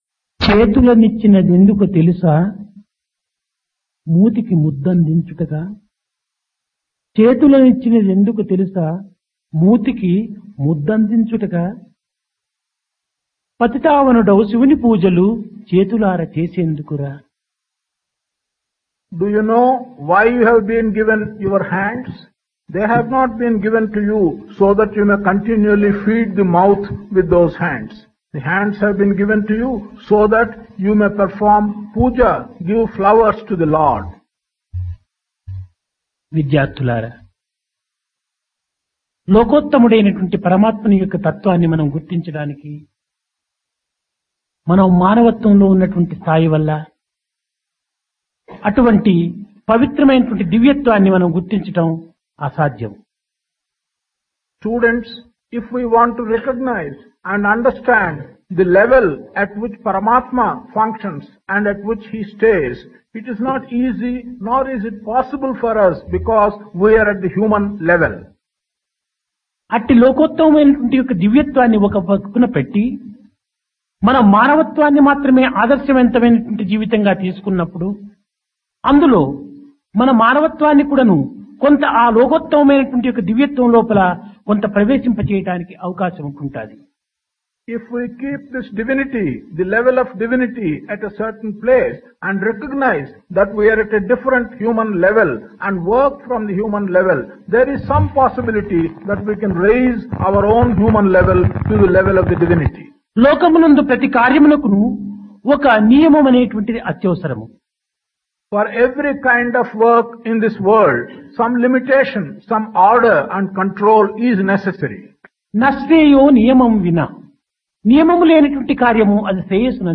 Divine Discourse of Bhagawan Sri Sathya Sai Baba, Summer Showers 1976
Occasion: Summer Course 1976 - Indian Culture and Spirituality